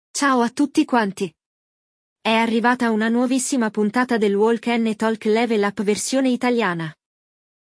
Nell’episodio di oggi ascolteremo la conversazione tra Greta e Rocco, una coppia che è andata al supermercato con il loro bebè Davide, che ha fame.
IL DIALOGO